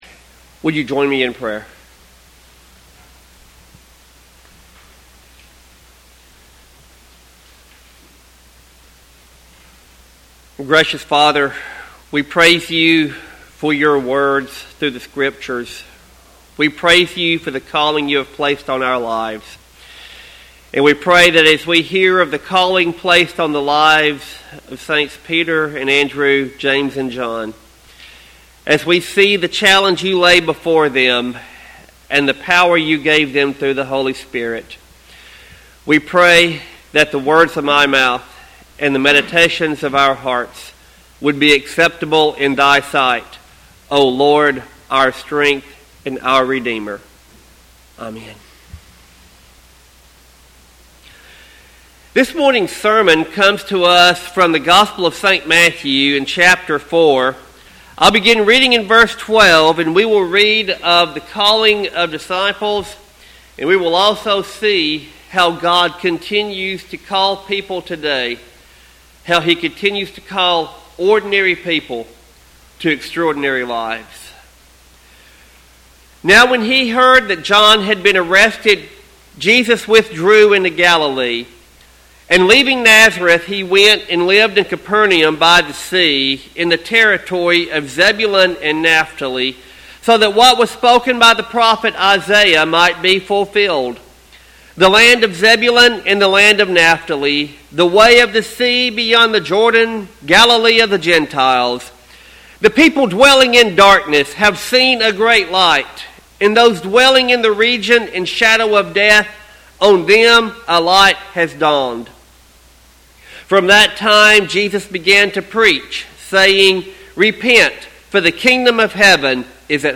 Sermon text: Matthew 4:12-23.